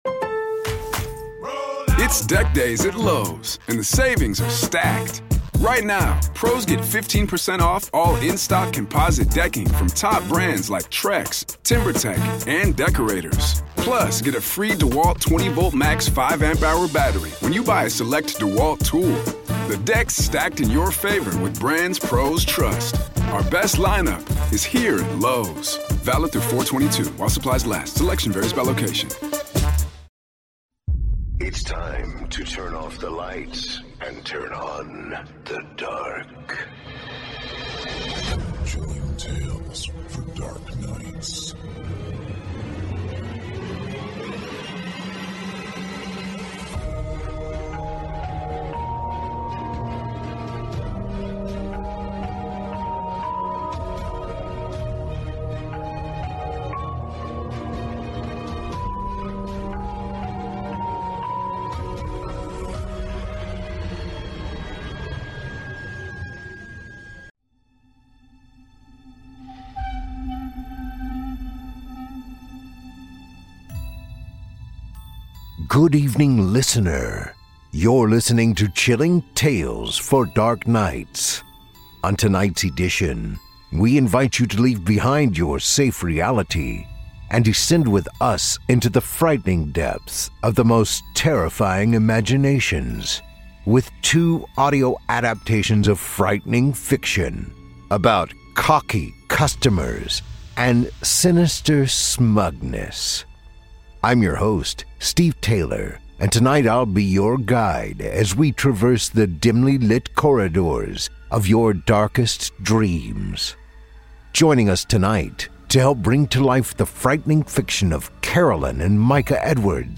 On tonight’s edition, we invite you to leave behind your safe reality, and descend with us into the frightening depths of the most terrifying imaginations, with two audio adaptations of frightening fiction, about cocky customers and sinister smugness.